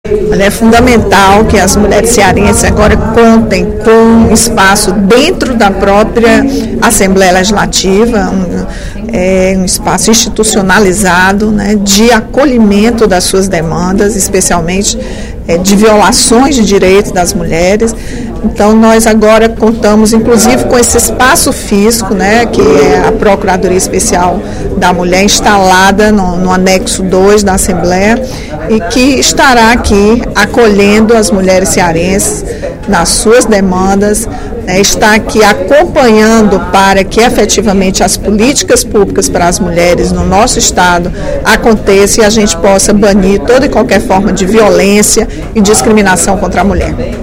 A titular da Procuradoria Especial da Mulher da Assembleia Legislativa, deputada Rachel Marques (PT), divulgou, na sessão plenária da Casa desta quinta-feira (05/07), os serviços e contatos do órgão, cuja sede foi inaugurada na última terça-feira (03/07).